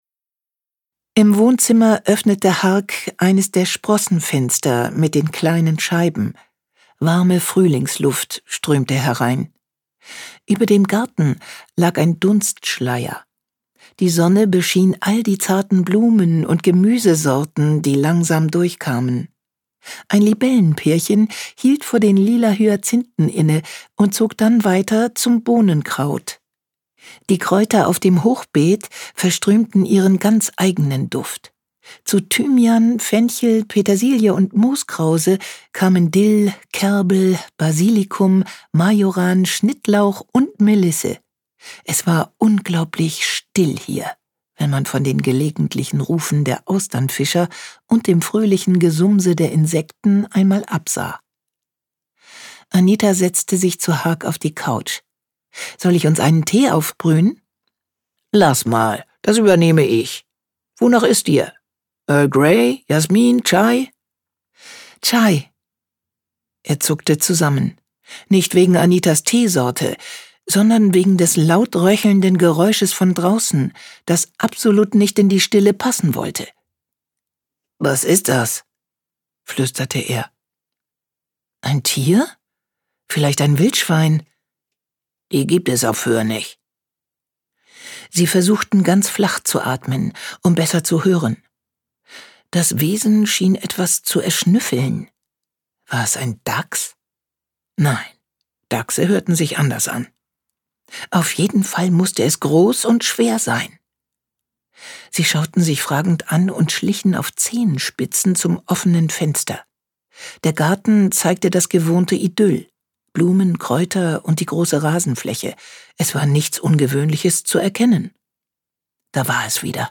Inselhochzeit im kleinen Friesencafé Ein Inselroman Janne Mommsen (Autor) Sabine Kaack (Sprecher) Audio Disc 2023 | 1.